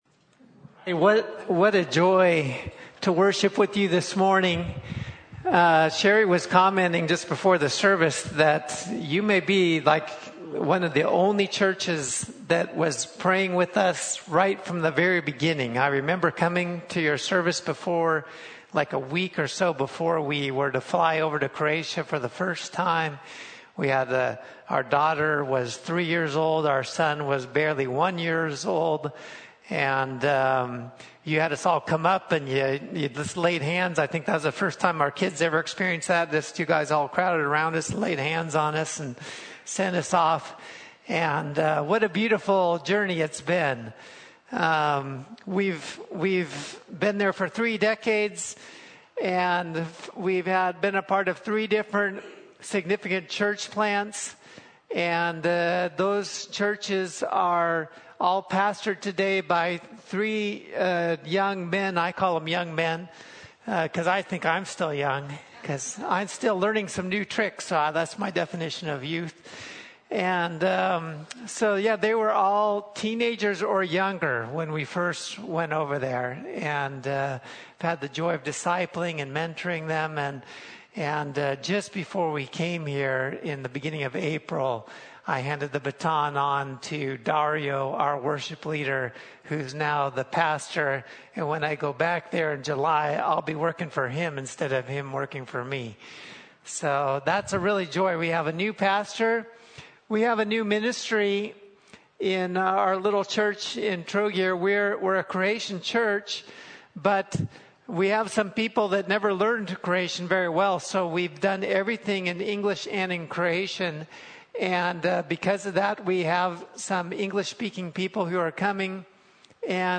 Topical Teachings Service Type: Sunday Morning